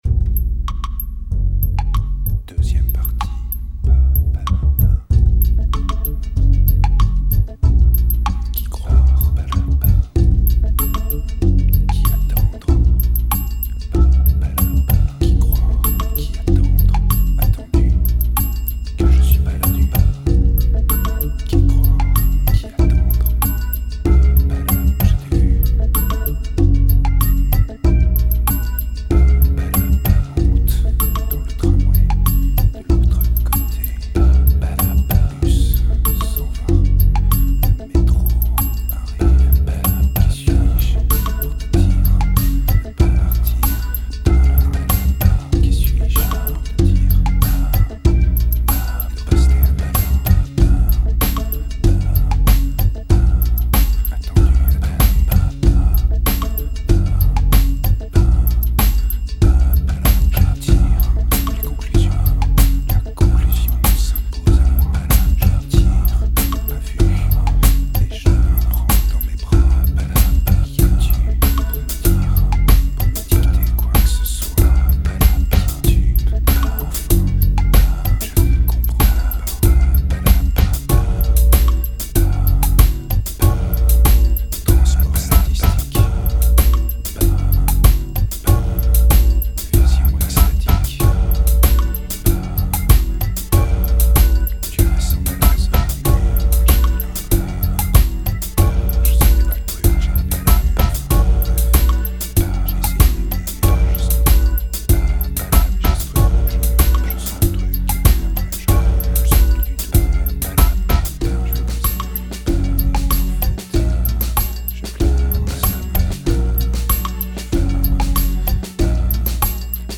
Solid Trip-hop Breakbeat Percusive